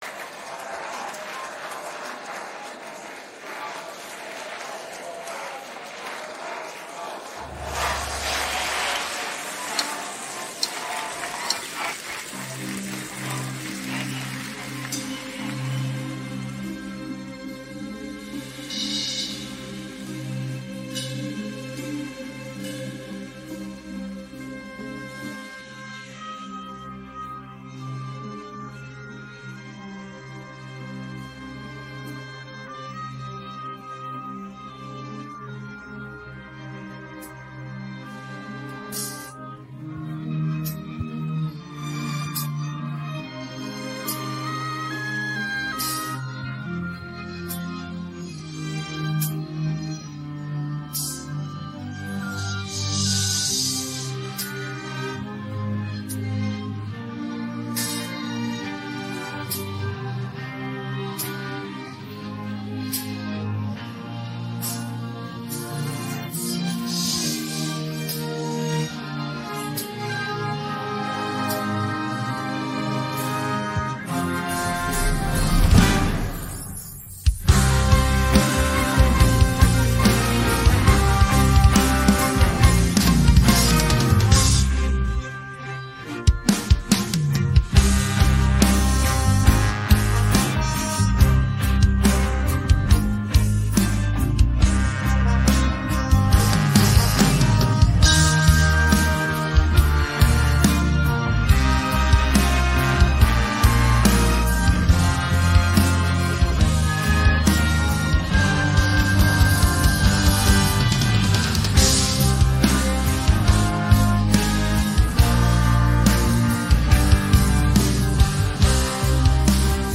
мінус караоке